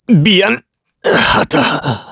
Clause_ice-Vox_Dead_kr_b.wav